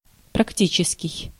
Ääntäminen
IPA: /pɾak.tis/